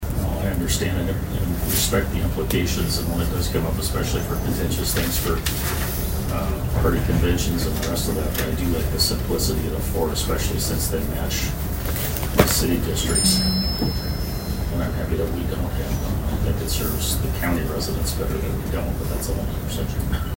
ABERDEEN, S.D.(HubCityRadio)- At Tuesday’s Brown County Commission meeting, the commissioners received a report from Brown County Auditor Lyn Heupel talking about the number of precincts within the city of Aberdeen.